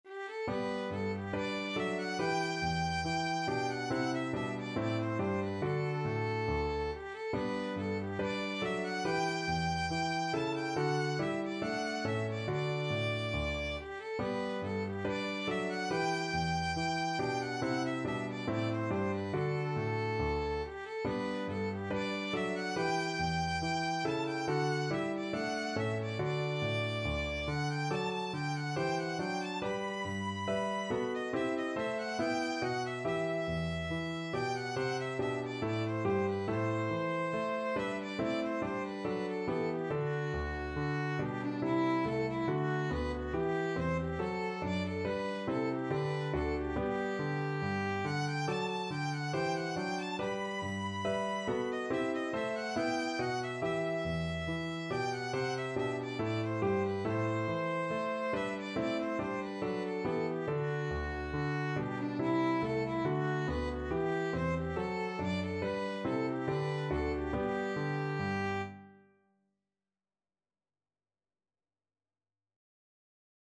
Violin
G major (Sounding Pitch) (View more G major Music for Violin )
4/4 (View more 4/4 Music)
D5-B6
Classical (View more Classical Violin Music)